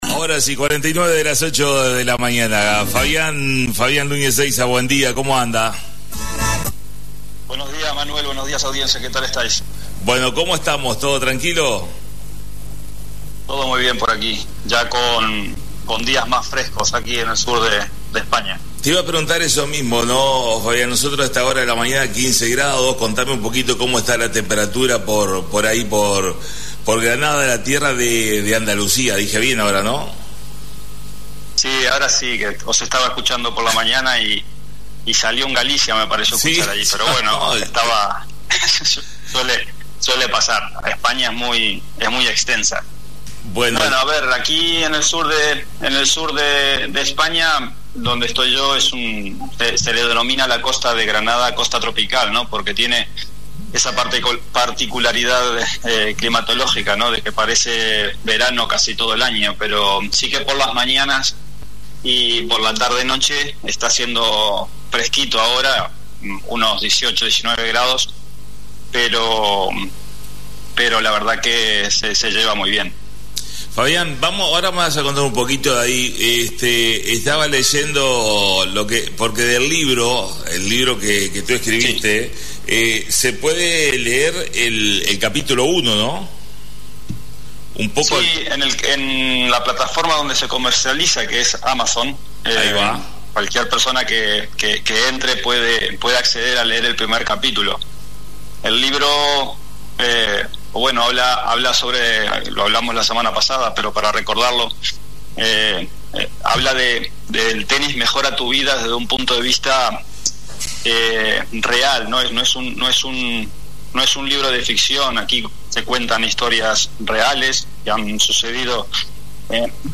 Comunicación en vivo